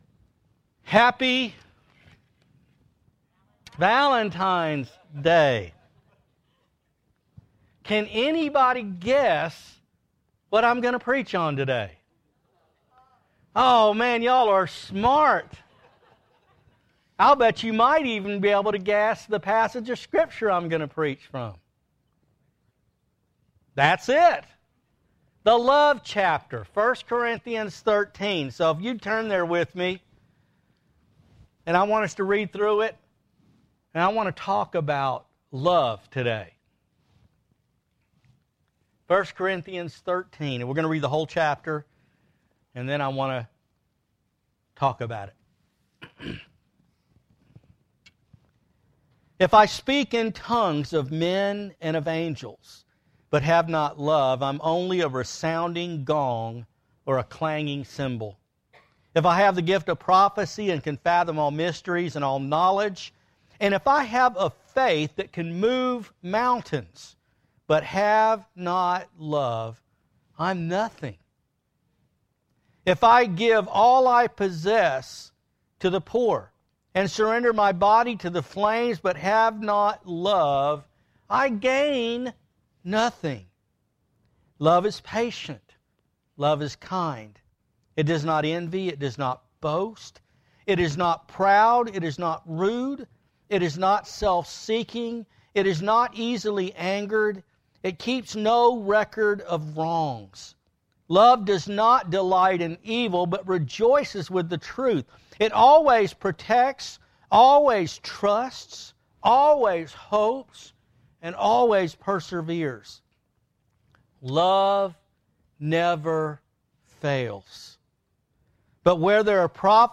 A Valentine’s Day sermon. What is the real meaning of love, and do we love God the way we should?